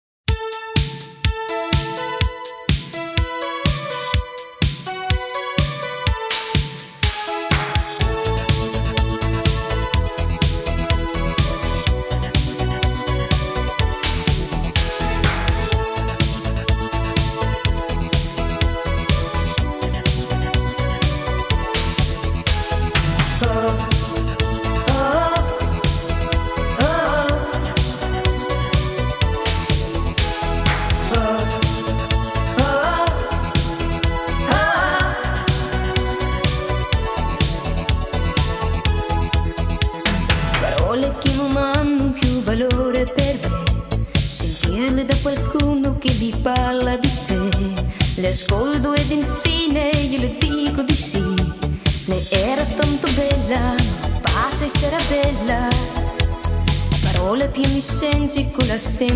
ポジティヴでロマンティックなメロディーにコケティッシュなイタリア語ヴォーカルが映えまくる逸品です。